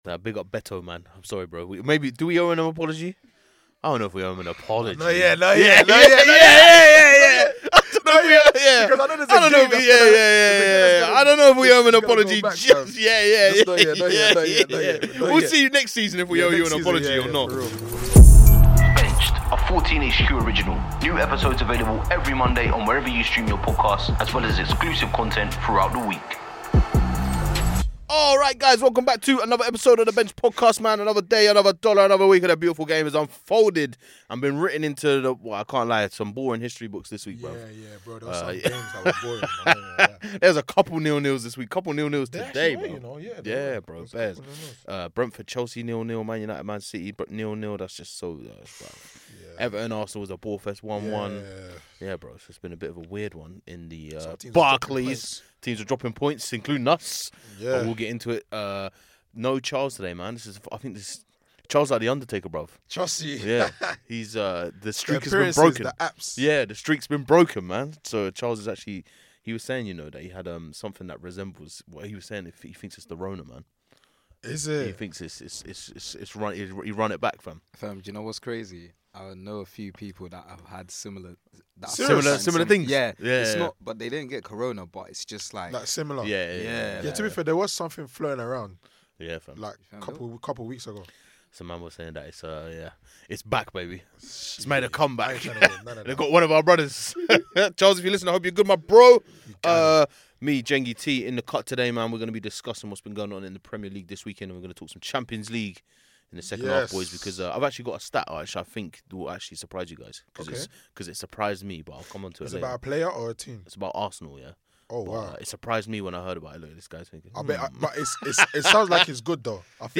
are in the studio talking over a damaging defeat for United at home to Liverpool. Arsenal and Chelsea drop points at home also as Haaland fires yet another hat trick.